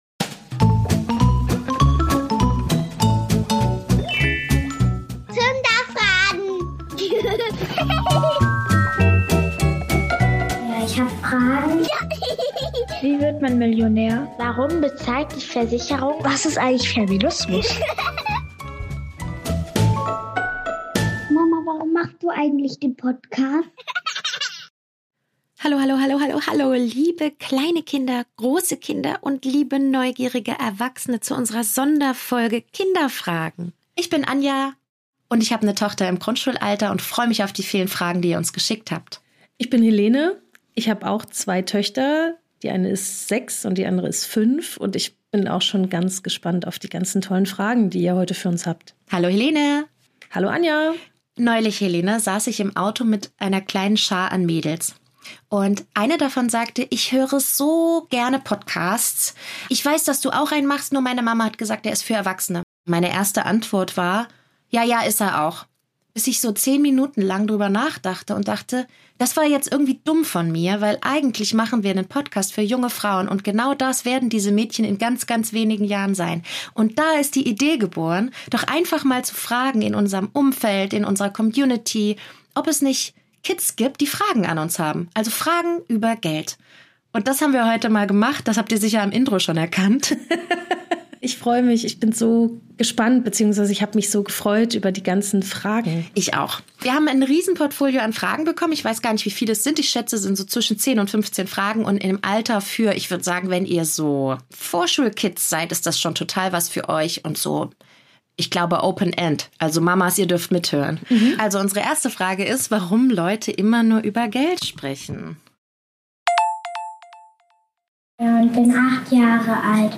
In dieser besonderen Folge von FAIRstärkung haben Kinder das Ruder übernommen – mit klugen, lustigen und bewegenden Fragen rund ums Thema Geld.